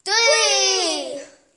女机器人
描述：机器人就像女声一样，用瑞典语计算到30。
标签： 计数 谈话 计数 语音 女性 数字 讲话 谈话 机器人 ailien 女人 数字 瑞典
声道立体声